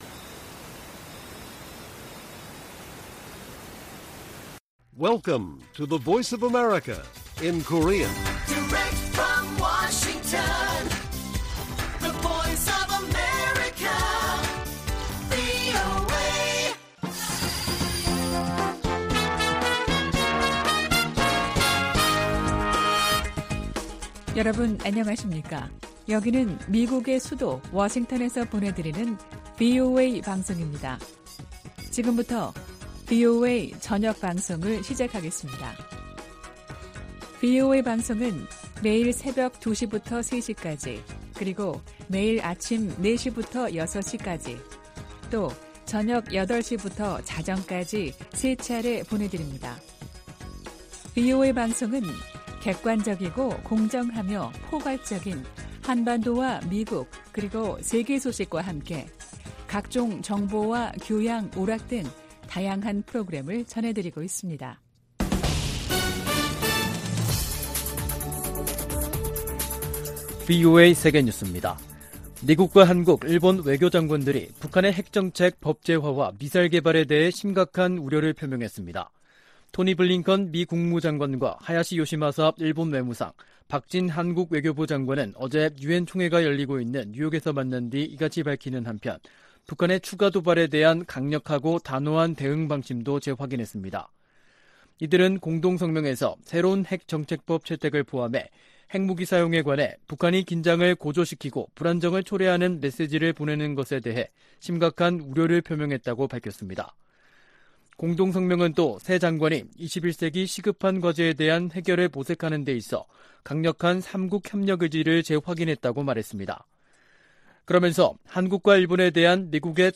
VOA 한국어 간판 뉴스 프로그램 '뉴스 투데이', 2022년 9월 23일 1부 방송입니다. 미 핵 추진 항공모함 로널드 레이건이 연합 훈련을 위해 부산에 입항했습니다. 미한일 외교장관들이 뉴욕에서 회담하고 북한의 핵 정책 법제화에 심각한 우려를 표시했습니다. 제이크 설리번 미국 국가안보보좌관은 북한의 7차 핵 실험 가능성이 여전하다고 밝혔습니다.